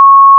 **🔊 SFX PLACEHOLDERS (23 WAV - 1.5MB):**
**⚠  NOTE:** Music/SFX are PLACEHOLDERS (simple tones)
sword_slash.wav